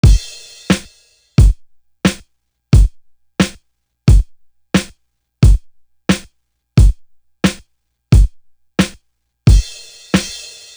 Tick Tock Drum.wav